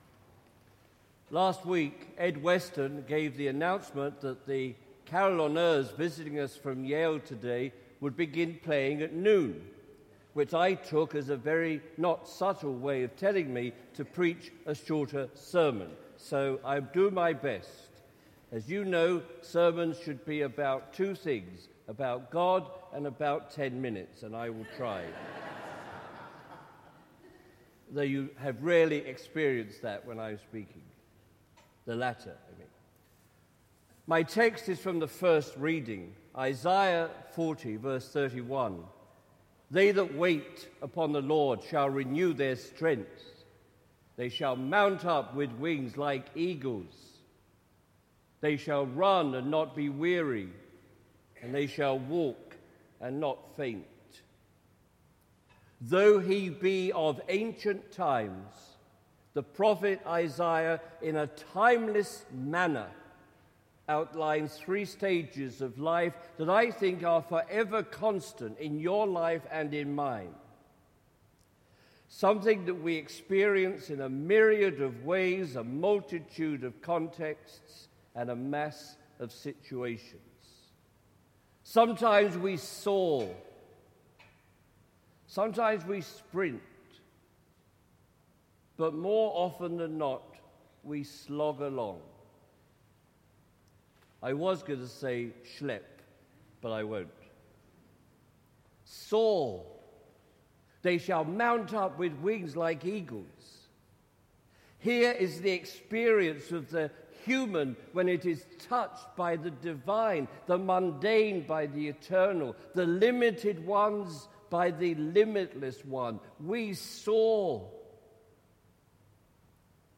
Yesterday’s sermon was all about the importance, the value of the daily slog, plod, schelp.